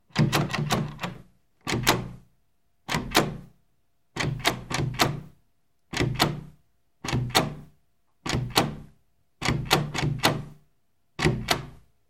На этой странице собраны реалистичные звуки ручки двери: скрипы, щелчки, плавные и резкие повороты.
Звук запертой деревянной двери на замок при многократном дёргании за ручку